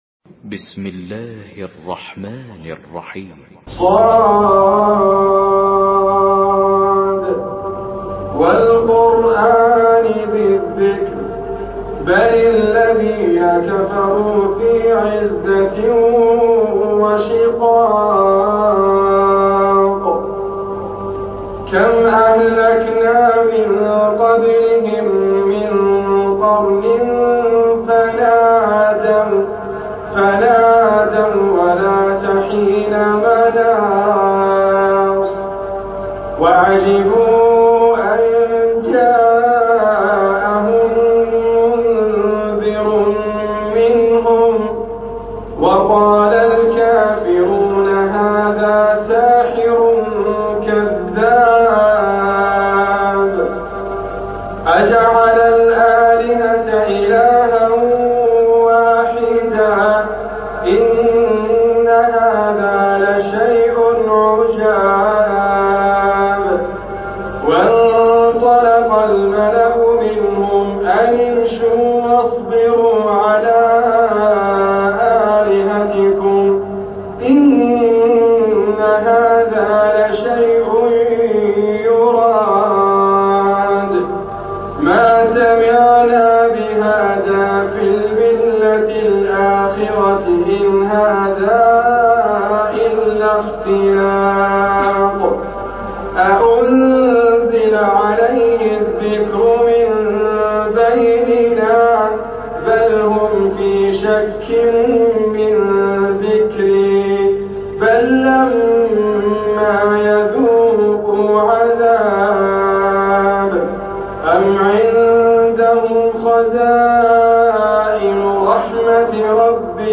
Surah Sad MP3 by Idriss Abkar in Hafs An Asim narration.
Murattal Hafs An Asim